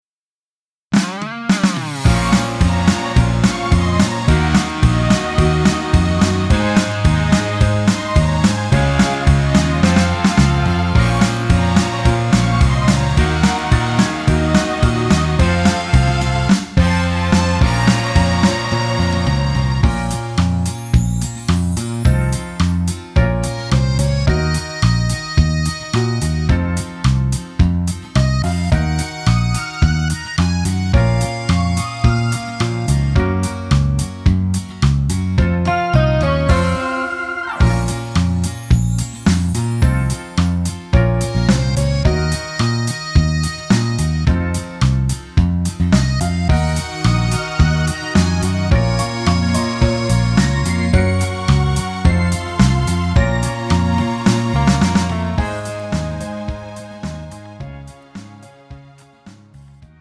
음정 여자키
장르 가요 구분 Pro MR